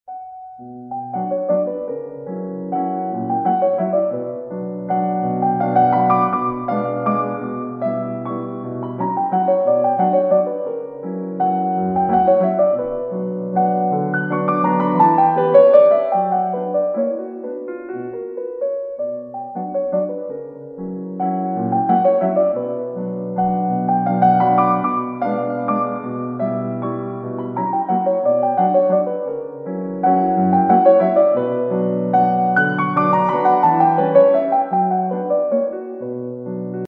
Рингтоны » Классические